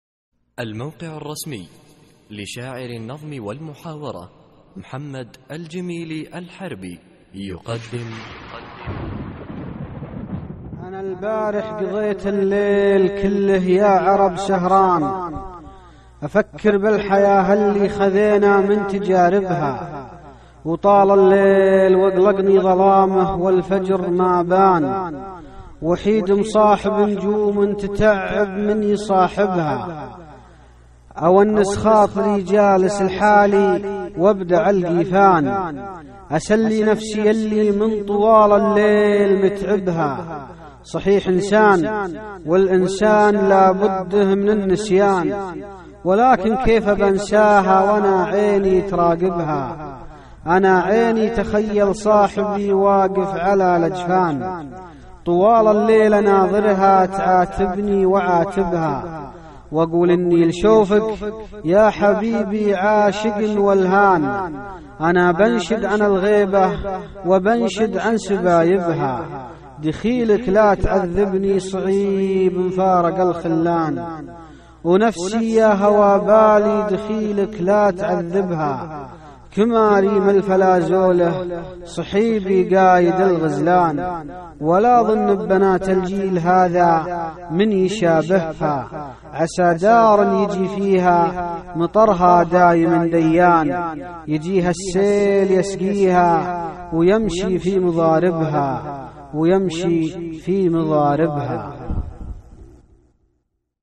القصـائــد الصوتية
اسم القصيدة : مفارق الخلان ~ إلقاء